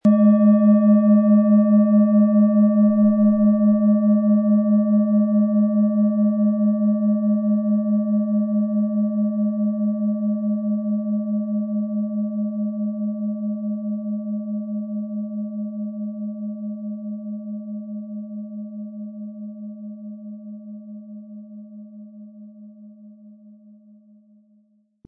Tibetische Universal-Schulter-Kopf- und Bauch-Klangschale, Ø 19,2 cm, 1000-1100 Gramm, mit Klöppel
Beim Speichern des Tones der Schale haben wir sie angespielt, um herauszubekommen, welche Körperregionen sie zum Schwingen bringen könnte.
Ein die Schale gut klingend lassender Schlegel liegt kostenfrei bei, er lässt die Planetenklangschale harmonisch und angenehm ertönen.
MaterialBronze